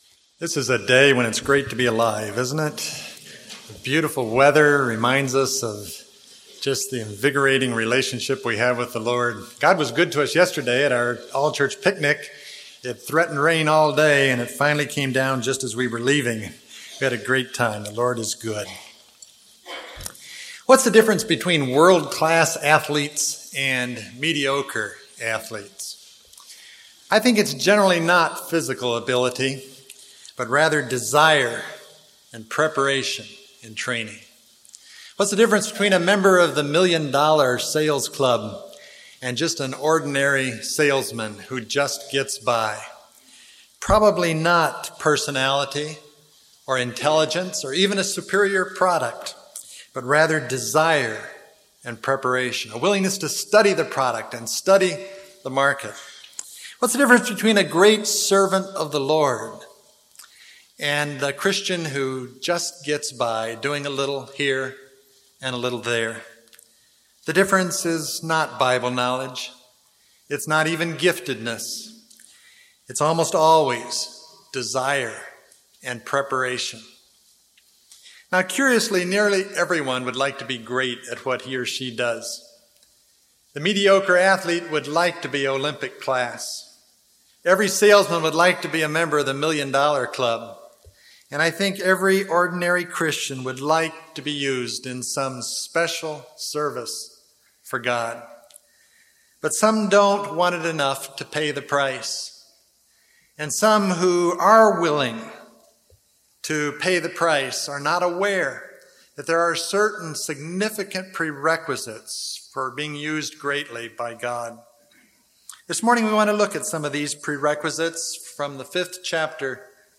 This morning we want to look at some of those prerequisites in regard to spiritual service as we read the fifth chapter of Joshua: Now when all the Amorite kings west of the Jordan and all the Canaanite kings along the coast heard how the LORD had dried up the Jordan before the Israelites until we had crossed over, their hearts melted and they no longer had the courage to face the Israelites.